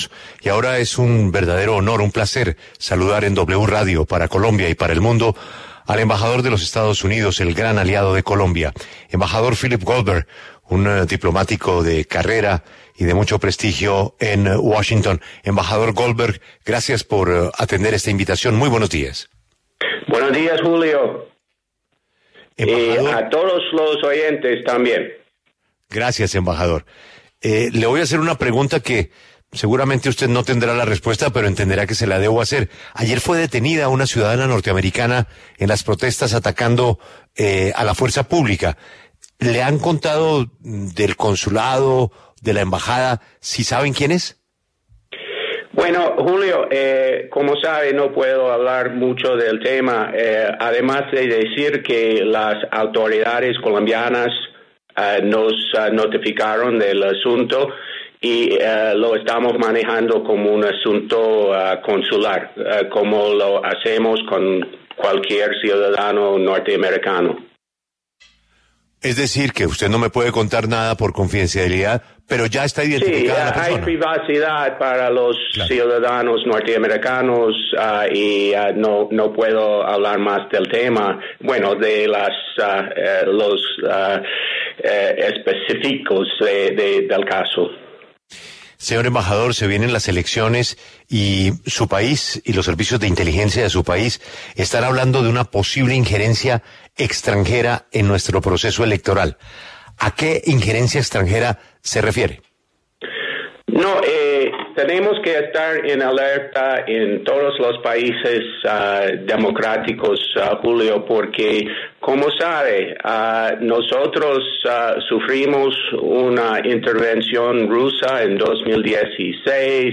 Philip Goldberg, embajador de Estados Unidos en Colombia, habló en La W a propósito del bicentenario de las relaciones diplomáticas entre ambos países.